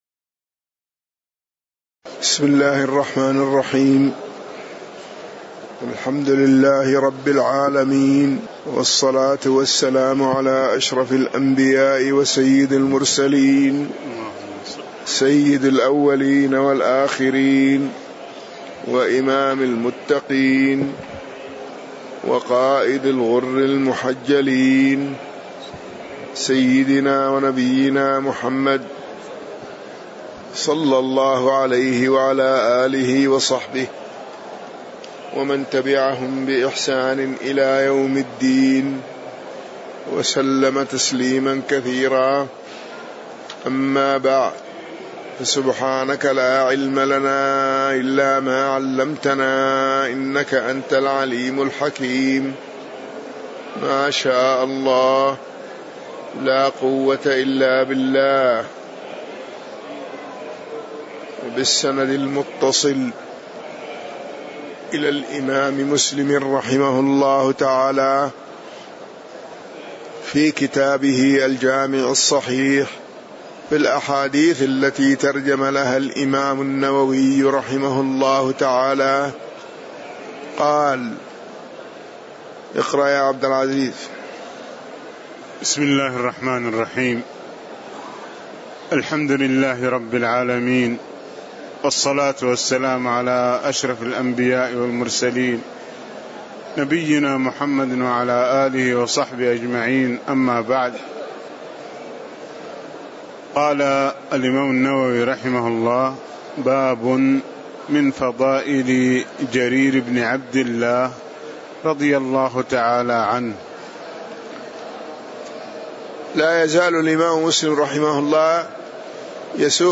تاريخ النشر ١٥ شوال ١٤٣٧ هـ المكان: المسجد النبوي الشيخ